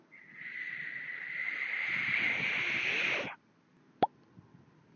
描述：贯穿立体光谱的蜱虫
标签： 搞笑 无聊的
声道立体声